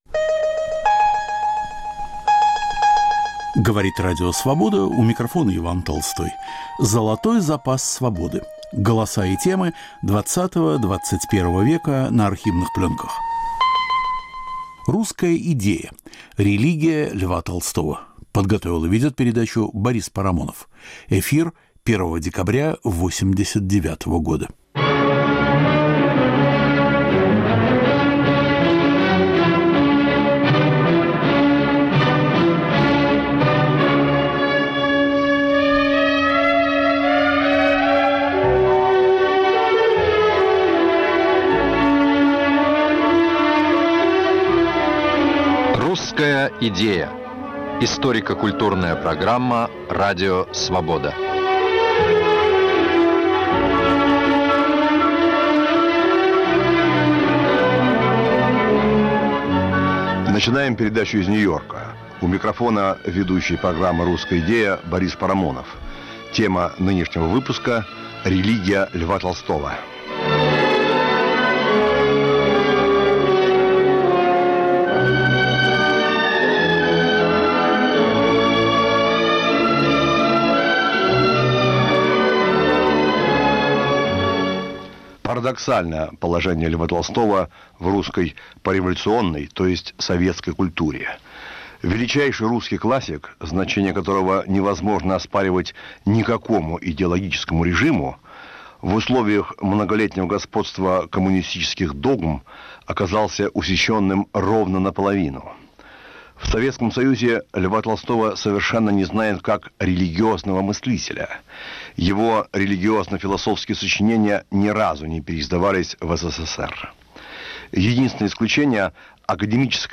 Архивный проект.